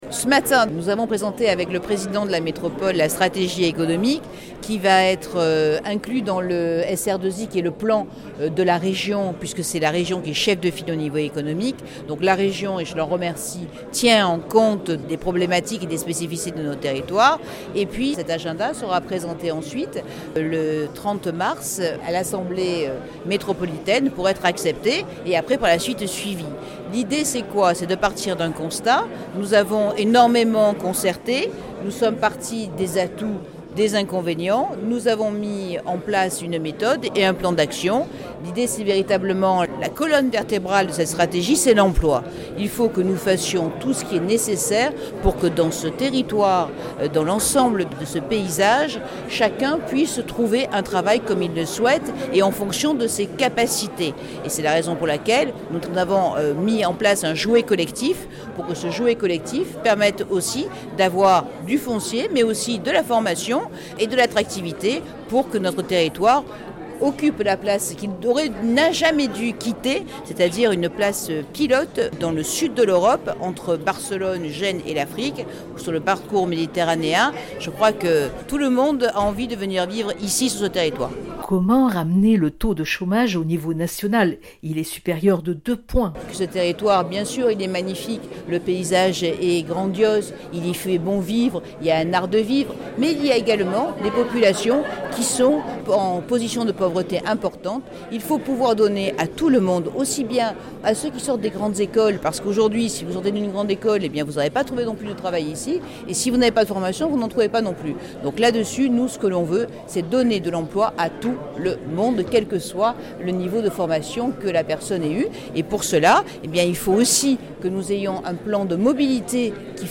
Après le vote de l’agenda des transports en décembre 2016 , c’est du deuxième temps fort du calendrier métropolitain dont il a été question ce lundi 13 mars au sein du Palais du Pharo à Marseille: le développement économique. Le président de la métropole, Jean-Claude Gaudin plantera le décor pour donner ensuite la parole à Martine Vassal, la première vice-présidente en charge de l’Économie, et présidente du département des Bouches-du-Rhône pour présenter la feuille de route à la presse et devant un aréopage de personnalités du monde économique.